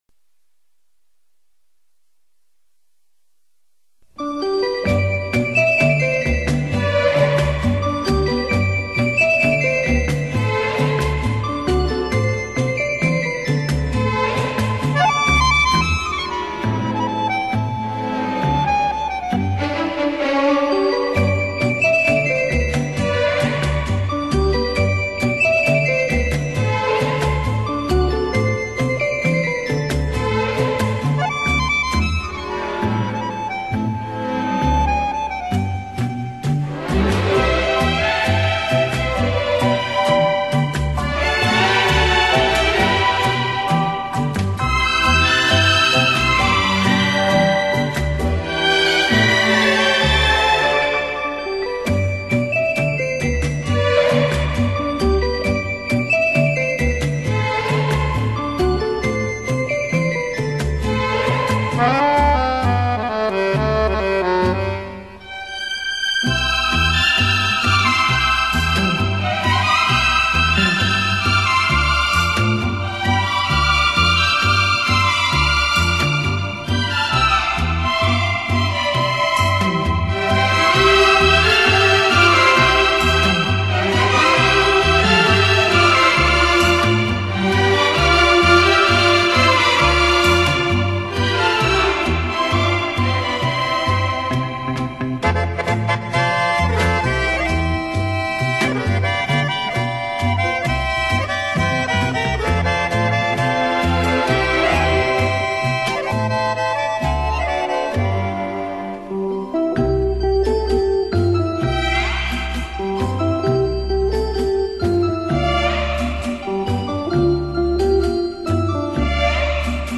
Originally this album was recorded in 1968.